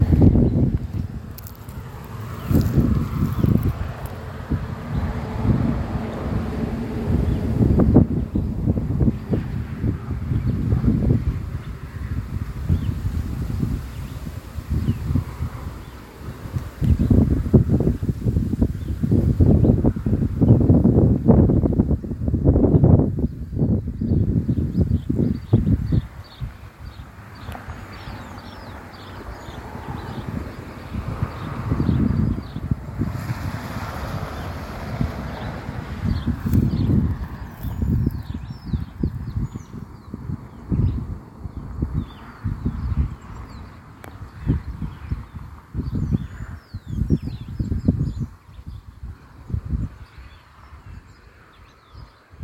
Chivi Vireo (Vireo chivi)
Location or protected area: Dique El Cadillal
Condition: Wild
Certainty: Recorded vocal